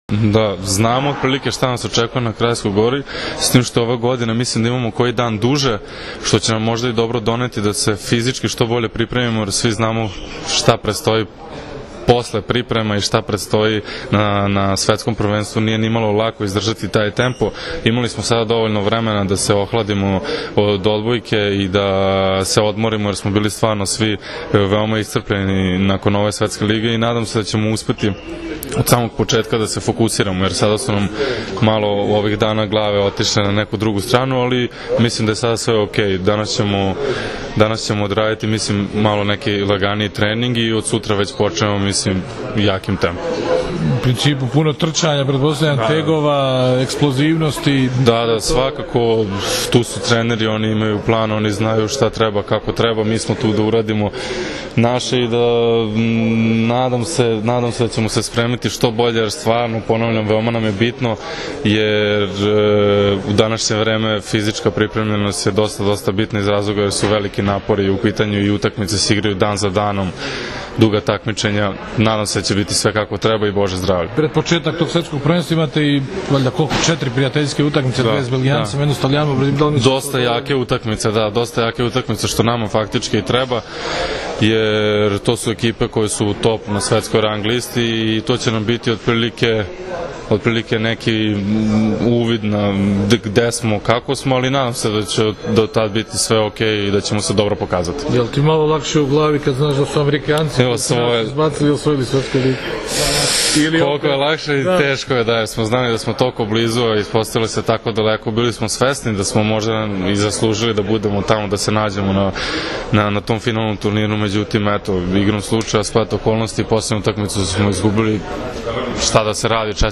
IZJAVA NIKOLE JOVOVIĆA